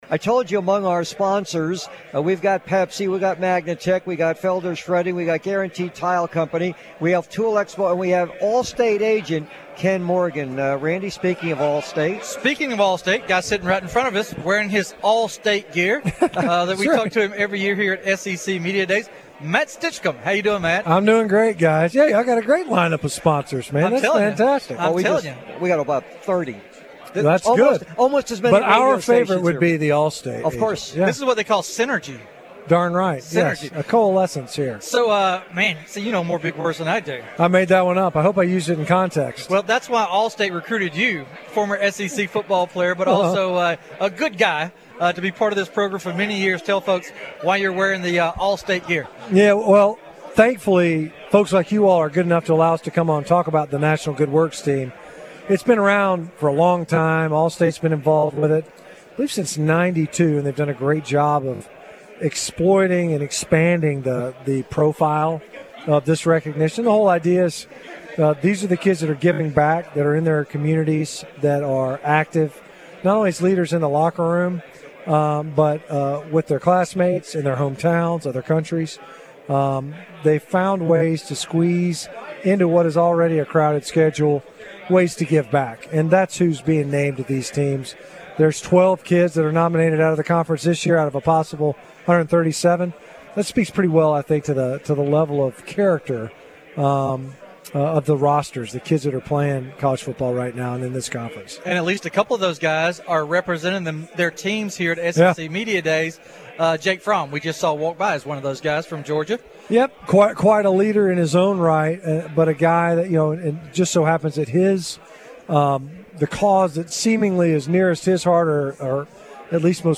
WNSP’s best interviews from SEC Media Days 2019!